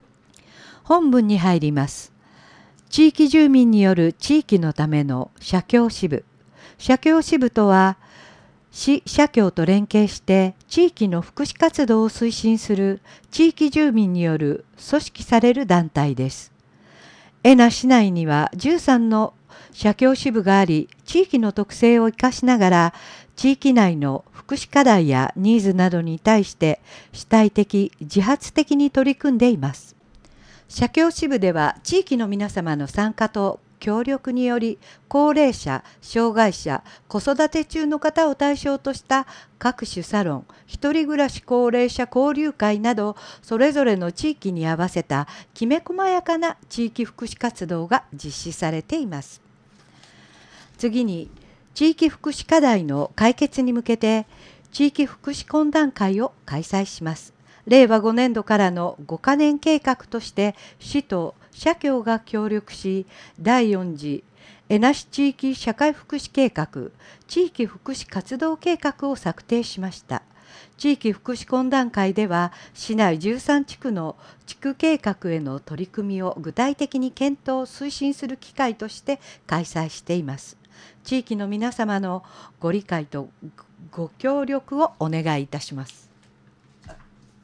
広報紙音声版はこちら↓